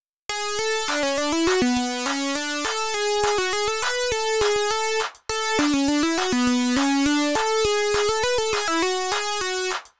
はい、だいぶ気持ち悪いことになりました。